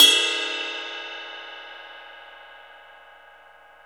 CYM XRIDE 4D.wav